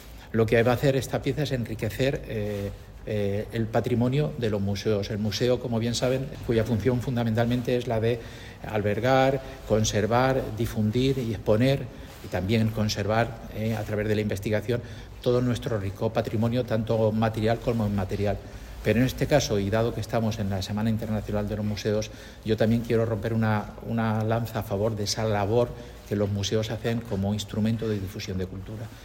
El delegado provincial de Cultura, Diego Pérez, ha participado en la presentación de la zafa de Hellín, adquirida para el Museo Provincial de Albacete.